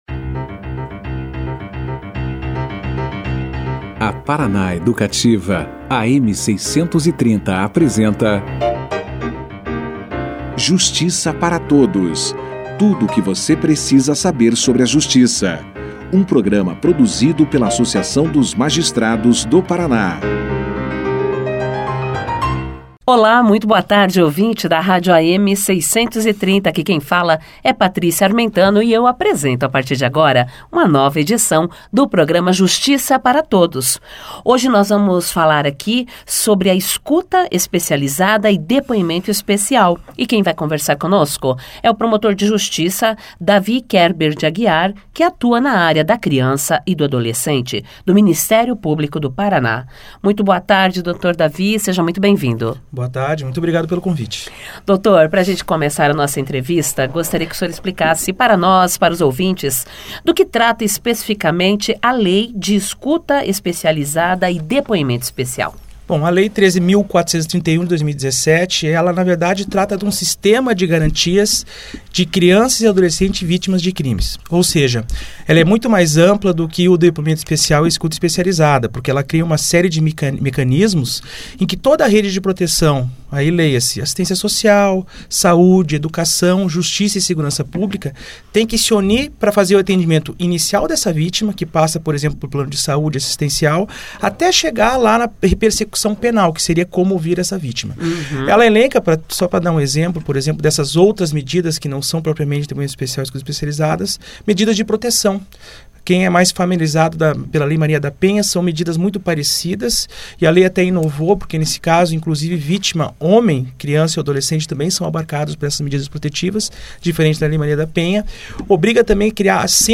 Os temas da entrevista foram a escuta especializada e o depoimento especial. Questões relacionadas aos novos procedimentos utilizados em audiências com crianças e adolescentes vítimas ou testemunhas de violência sexual foram esclarecidas pelo convidado. Além de ressaltar os objetivos da nova legislação, o convidado apontou as principais mudanças trazidas por ela referente aos depoimentos especiais.